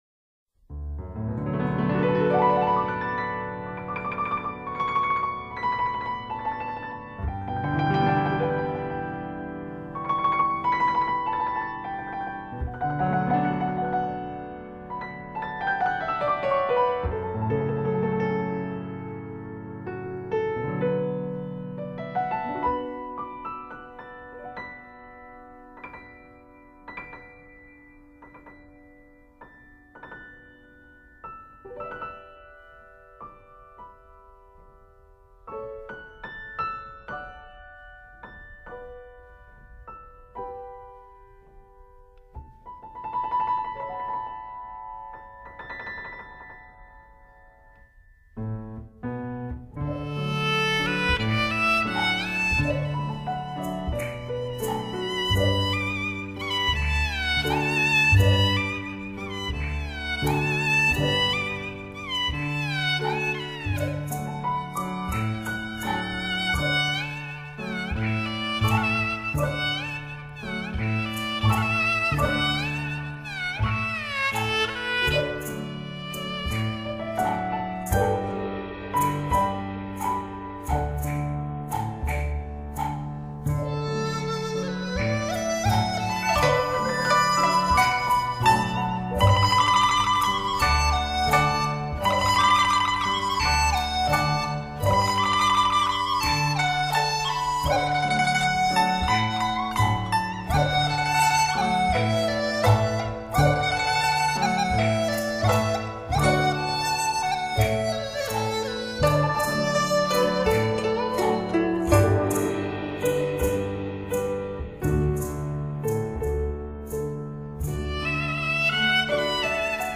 其试音碟也是精选多家发烧唱片公司的精采录音作品.